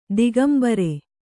♪ digambare